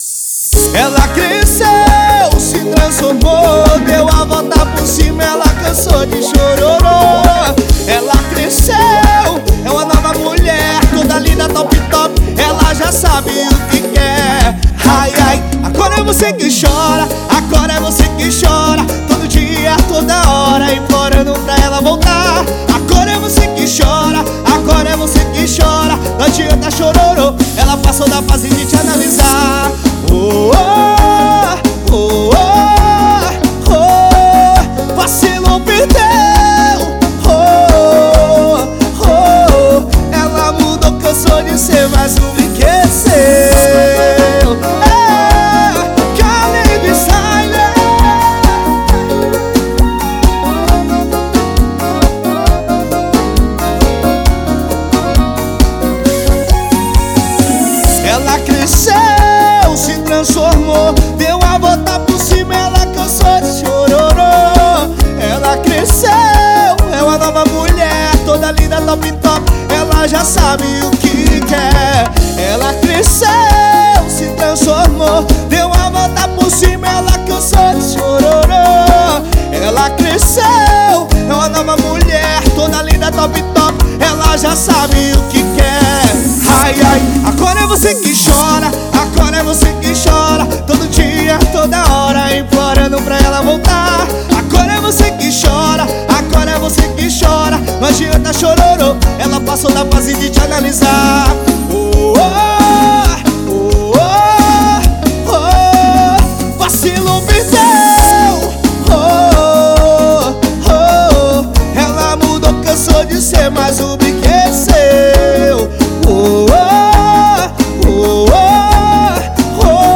EstiloRomântico